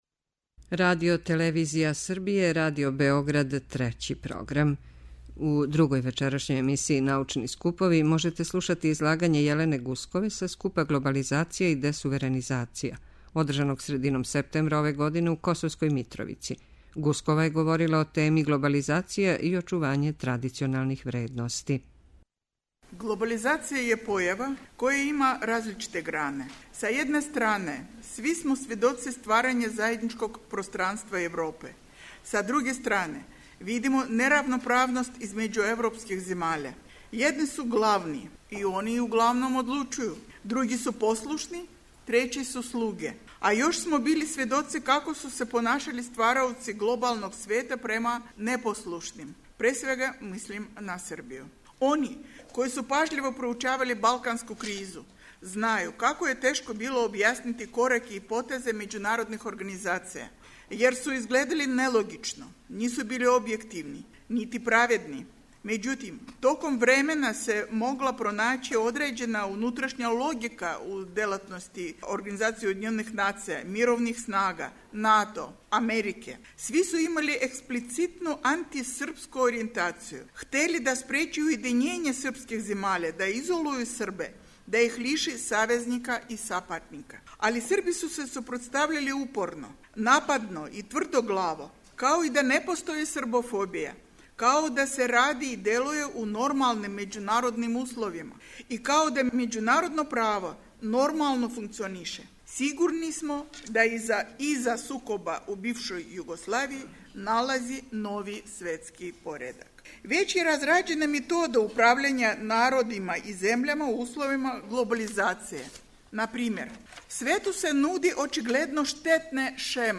одржаног средином септембра ове године у Косовској Митровици.